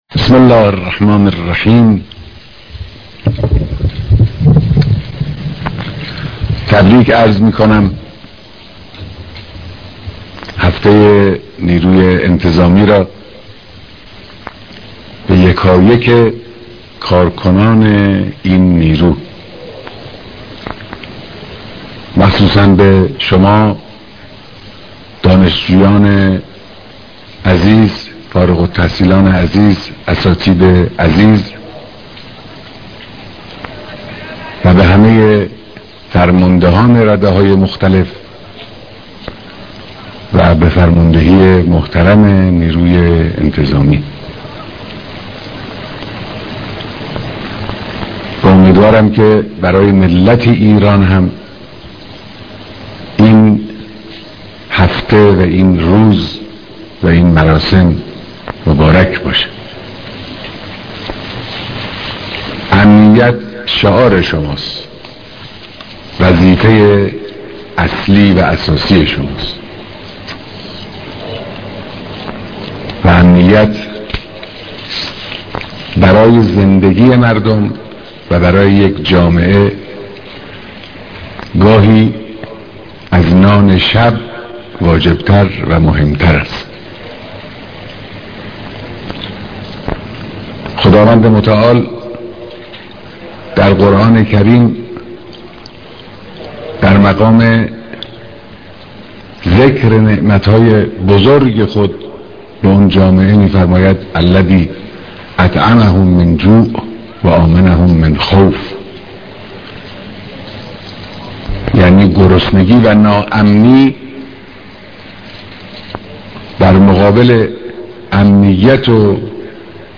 بيانات در مراسم فارغالتحصيلى و تحليف دانشكده افسرى نيروى انتظامى